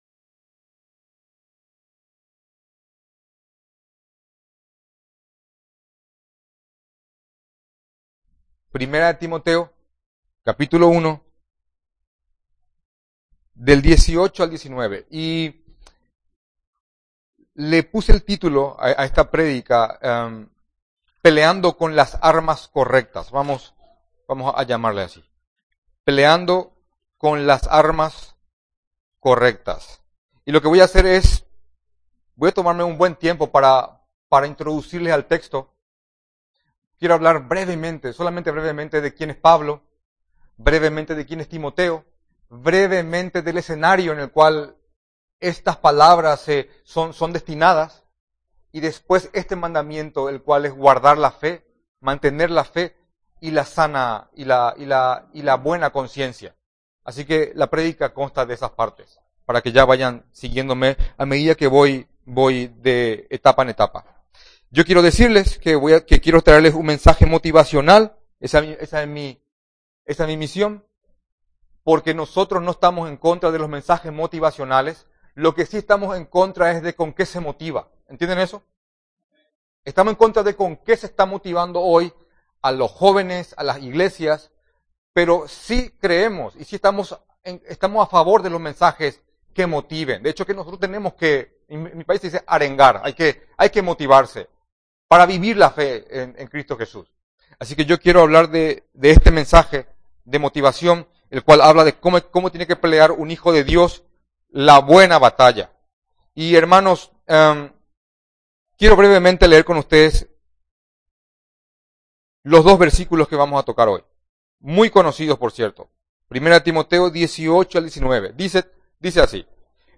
Audio del sermón
MENSAJE DOMINICAL IGLESIA BIBLICA TU FORTALEZA